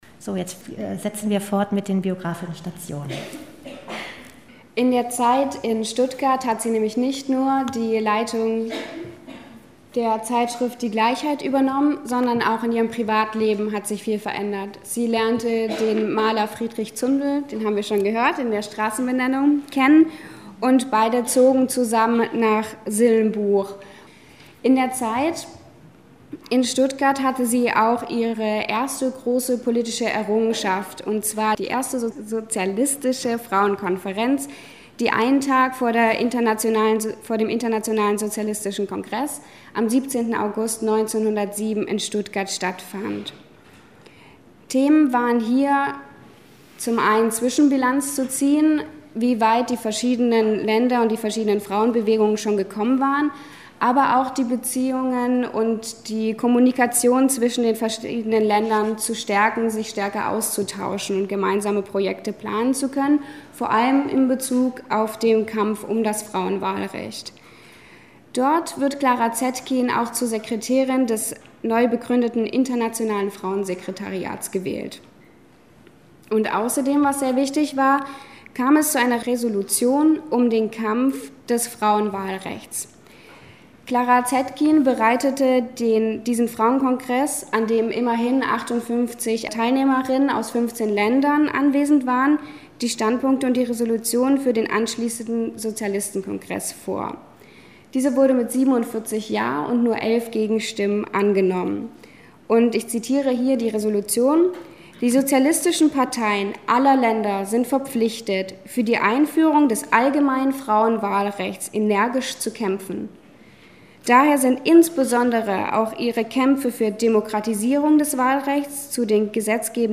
Vortrag über das Leben der Frauenrechtlerin Clara Zetkin